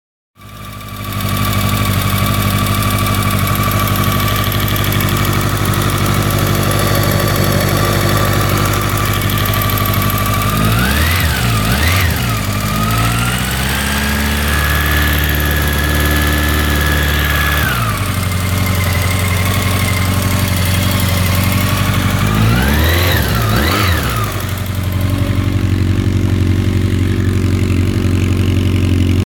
Ich habe mal mit einem Mobiltelefon die Geräuschkulissen von rechter und linker Motorseite aufgenommen. Ich finde man kann ganz deutlich hören, das es rechts viel lauter ist. Das Geräusch ist nicht abhängig von Last und Motortemperatur.